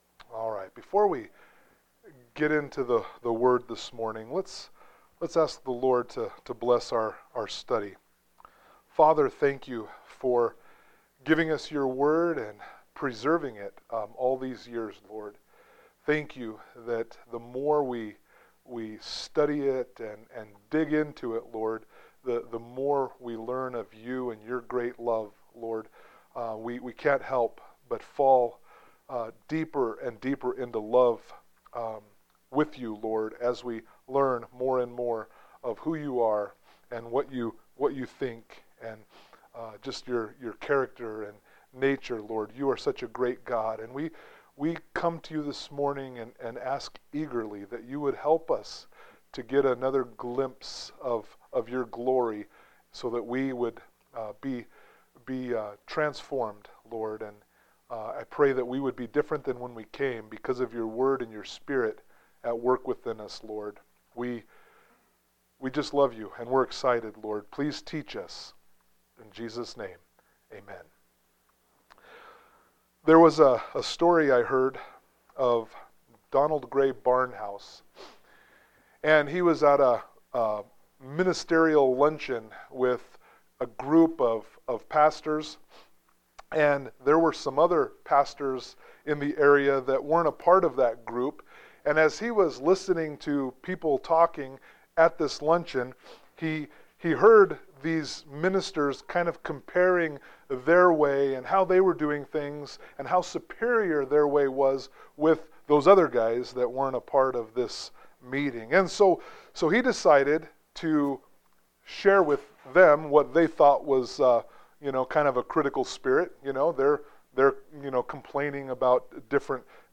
Romans 14:13-23 Service Type: Sunday Morning Worship « Romans 14:1-12